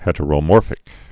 (hĕtə-rō-môrfĭk)